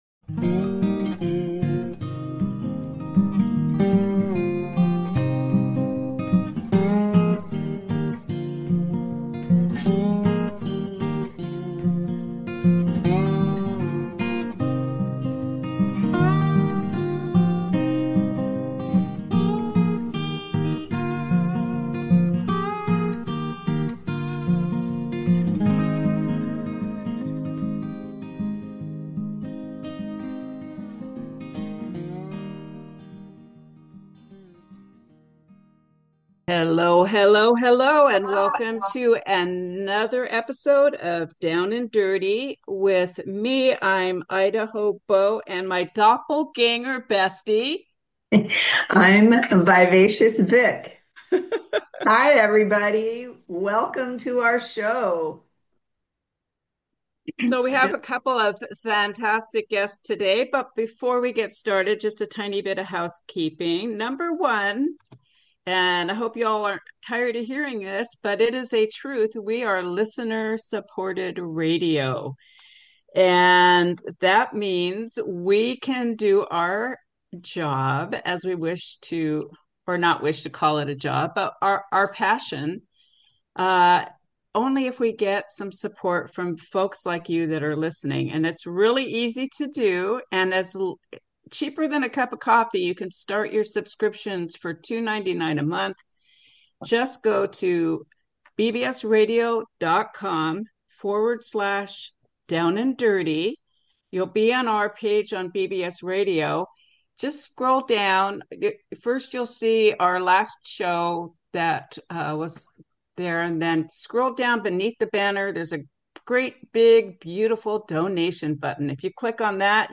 Talk Show
Expect lively chats with guest experts and answers to the big question: Got land?